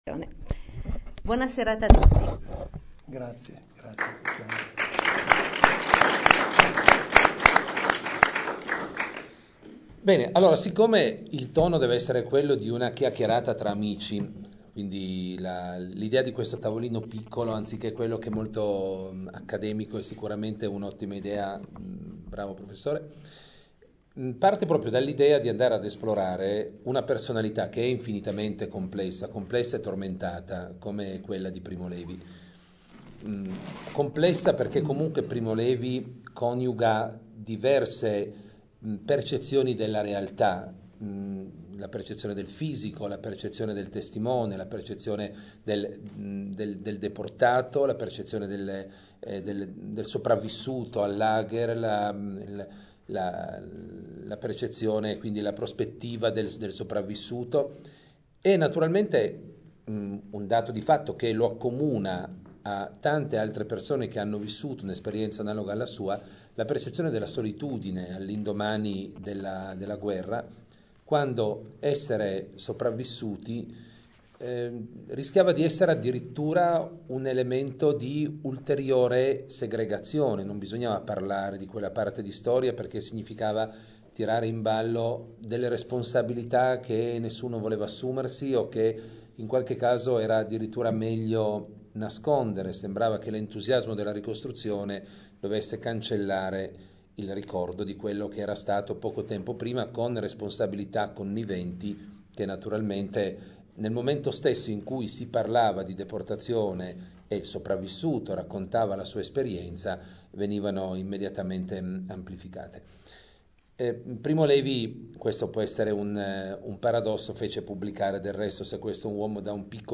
REGISTRAZIONE DELL’INCONTRO ALLA PAGINA: